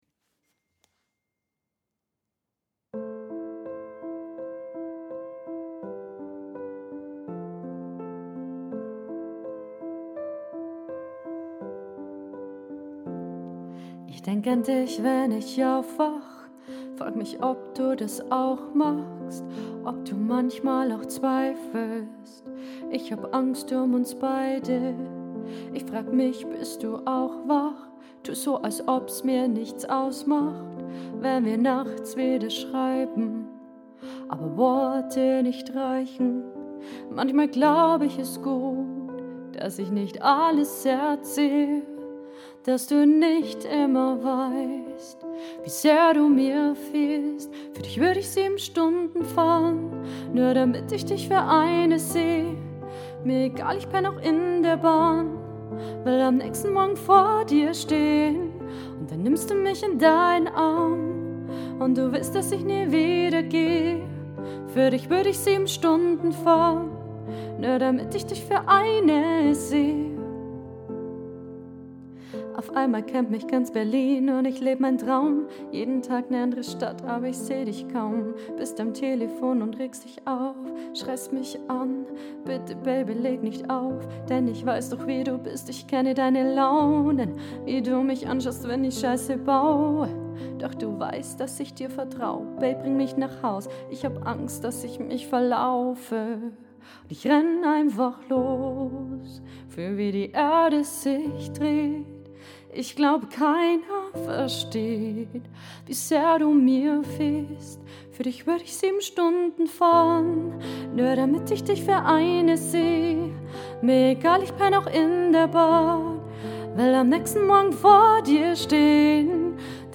romantic Cover Songs